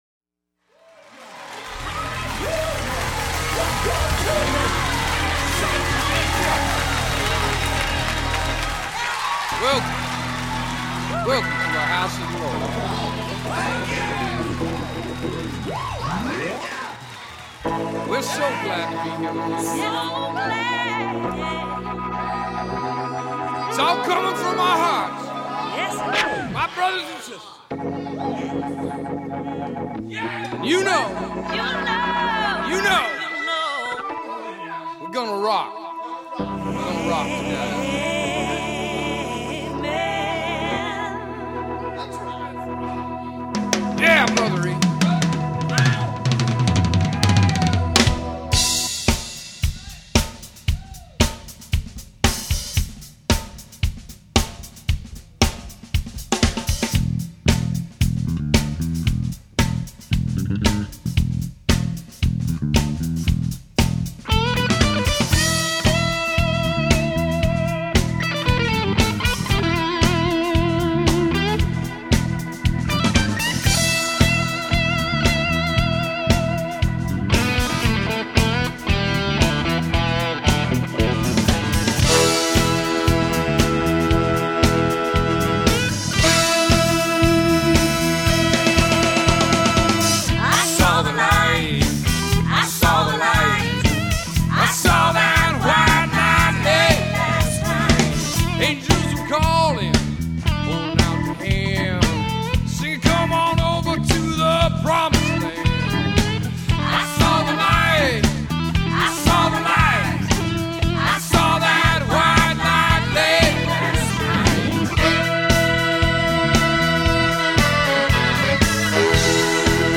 RECORDED at Arcade Digital Studios, Tumwater, WA
Guitars
Keyboards
Drums
Bass